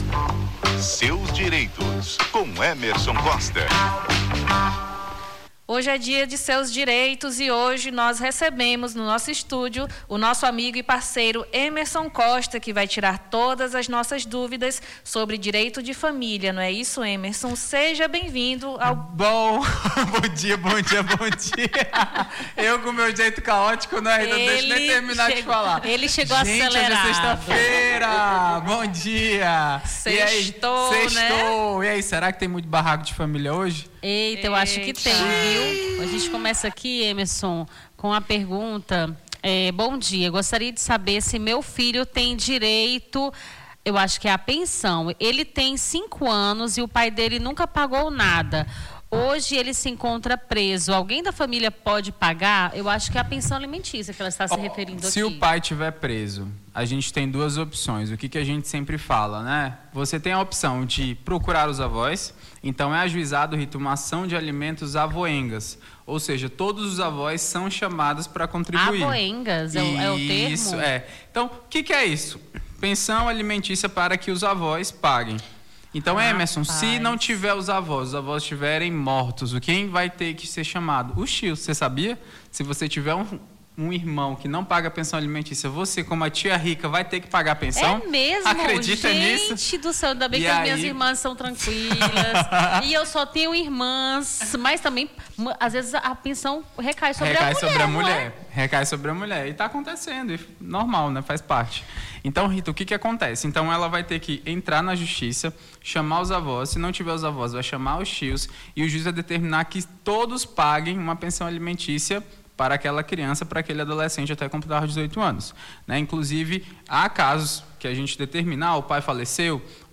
Seus Direitos: advogado esclarece dúvidas sobre direito de família